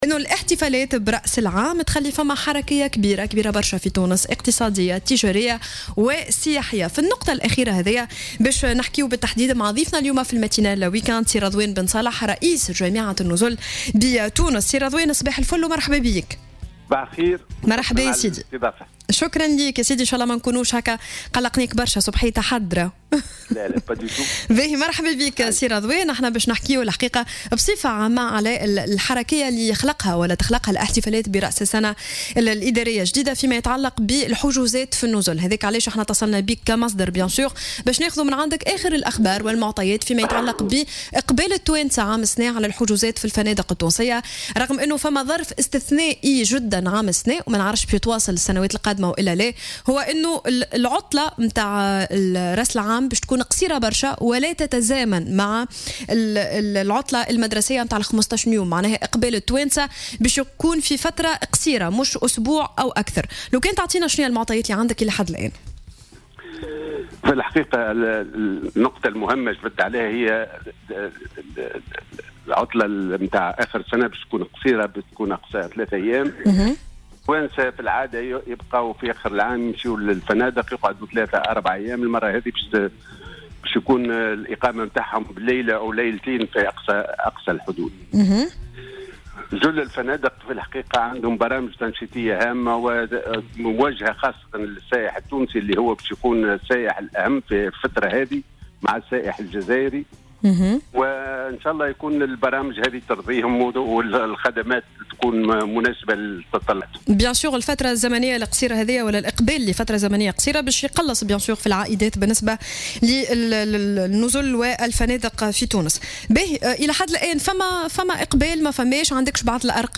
في حوار مع الجوهرة أف أم